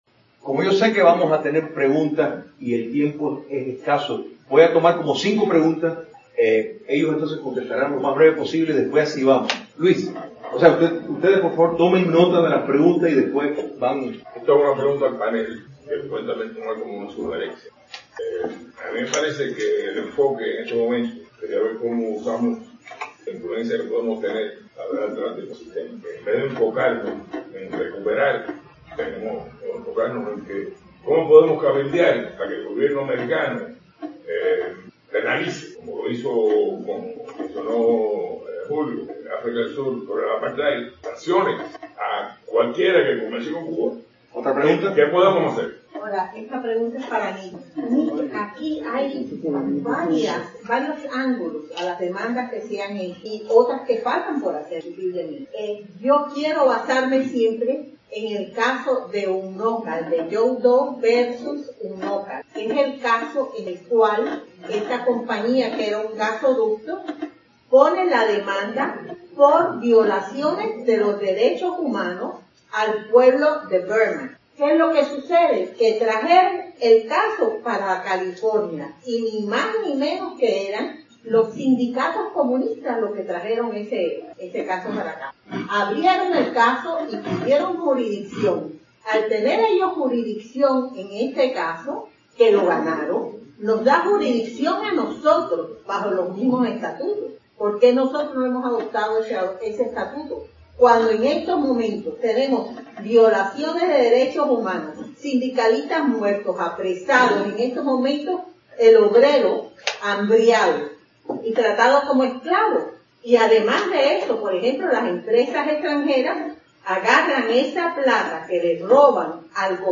Preguntas y respuestas - Simposio Ley Libertad (Helms-Burton) (Audio) | Patria de Martí
Preguntas y respuestas - Simposio Ley Libertad (Helms-Burton) (Audio) ("Patria de Martí", West Dade Regional Library, 21 marzo 2019)